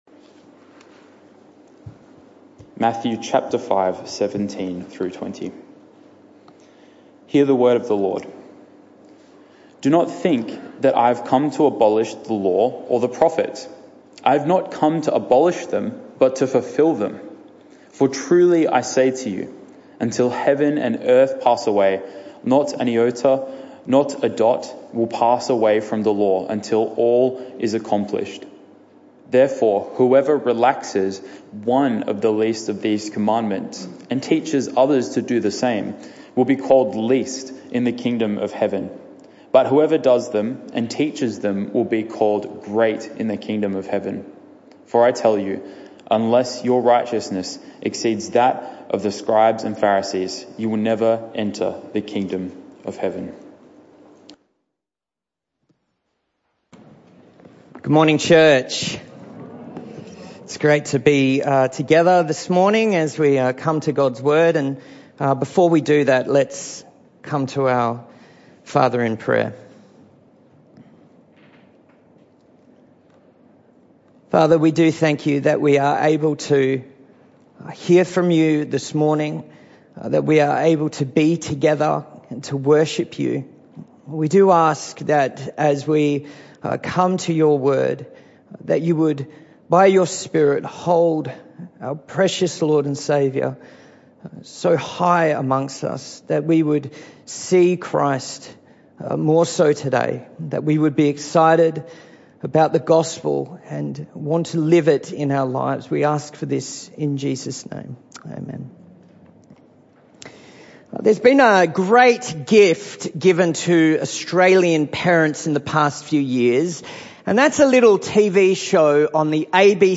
This talk was part of the AM Service series entitled The Message Of Matthew.